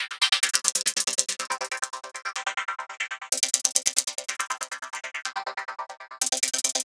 tx_perc_140_fiddlesticks.wav